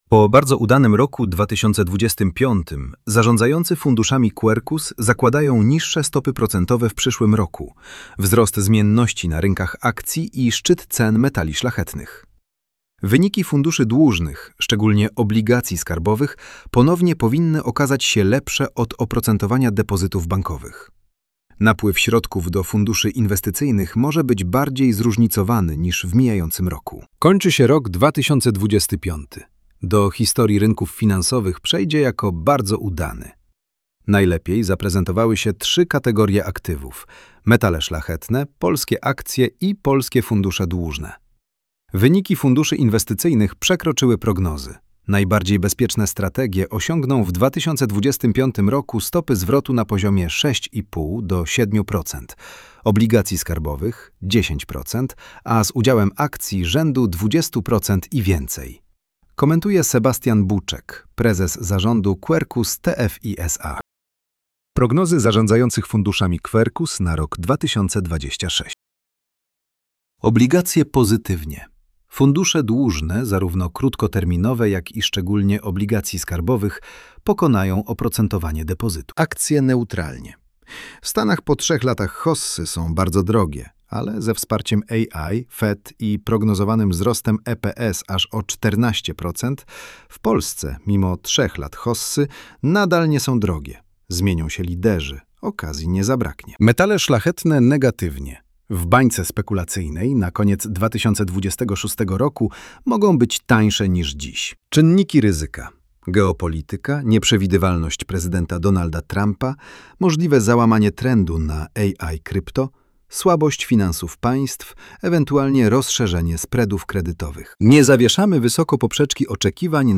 Komentarz dostępny jest także w formacie audio, który został wygenerowany za pośrednictwem aplikacji Elevenlabs: